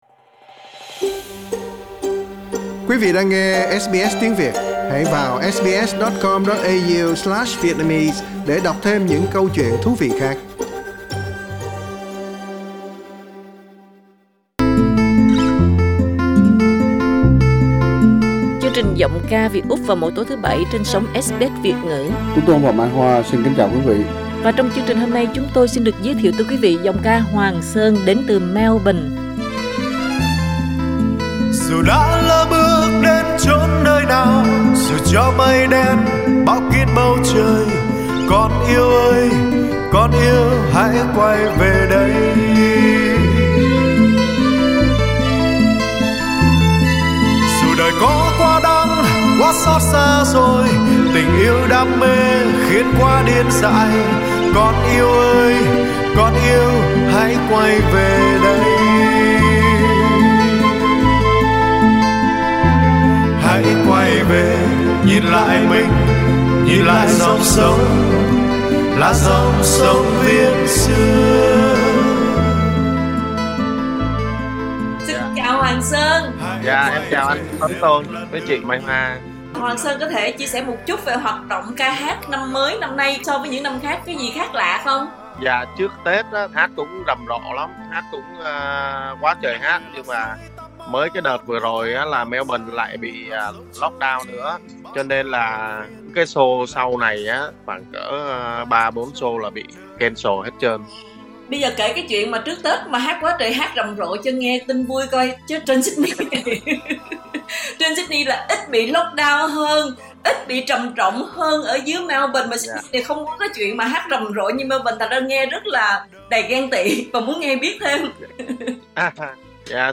một giọng ca rất mạnh từ Melbourne có cách trình bày đủ làm thỏa mãn người thưởng ngoạn khó tính và đem lại những khoảnh khắc âm nhạc phiêu bồng.
có một chất giọng riêng của mình gần giống với những ca sĩ miền Nam trước 75.